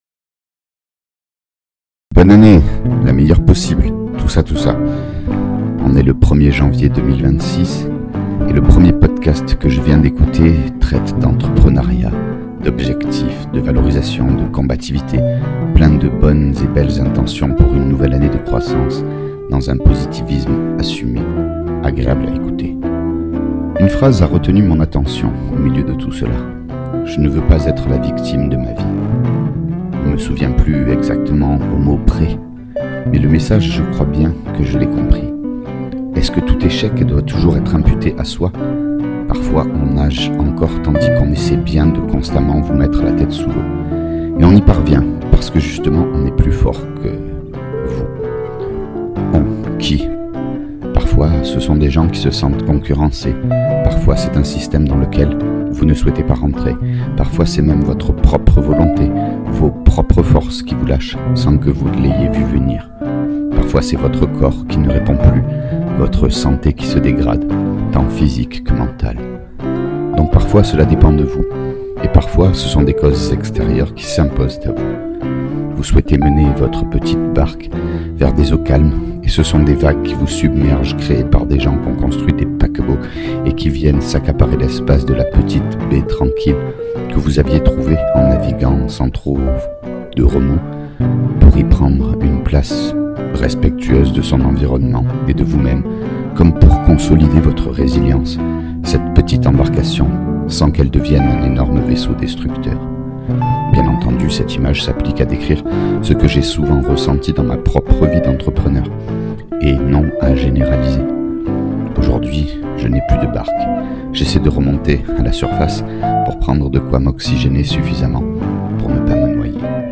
Le principe est tout simple : Un texte, comme une chronique, rédigé(e) avec un stylo sur un cahier (parce que c’est comme ça que j’écris), lu(e) devant un micro et accompagné(e) d’une improvisation à la guitare.
De plus je ne dispose pas d’un excellent matériel (un vieil ordi, une carte son externe M-Audio, un micro chant et une guitare) et je pense pouvoir améliorer la qualité du son, avec le temps et un peu d’investissement, mais prenez plutôt cet exercice comme une expérimentation de ma part, vous serez moins déçu(e)s. A noter : Les dates correspondent aux phases d’écriture, pas à l’enregistrement de la musique, mise en boîte quand il m’en vient la motivation.